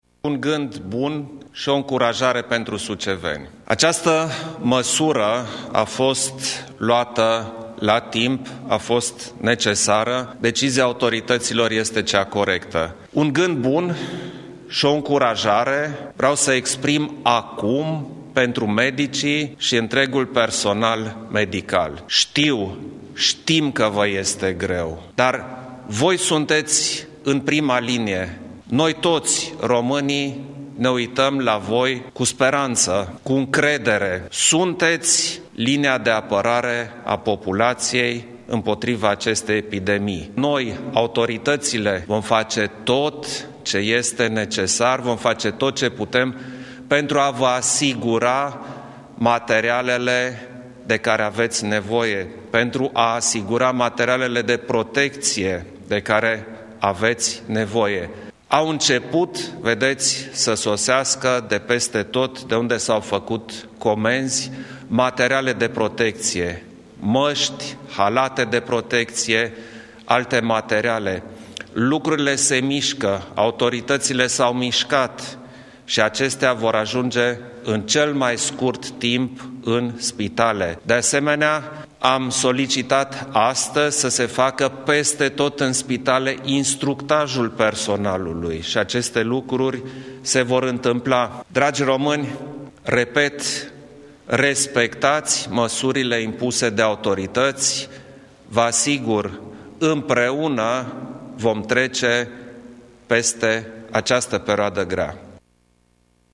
Preşedintele Klaus Iohannis a transmis, în urmă cu puțin timp, un mesaj de susținere pentru Suceava, care este acum în carantină , dar și pentru cadrele medicale, supuse în acest moment unei presiuni mari.
Declarațiile președintelui au venit după întâlnirea de urgență, de evaluare a măsurilor cu privire la gestionarea epidemiei COVID, avută cu premierul și mai mulți miniștri ai cabinetului Orban.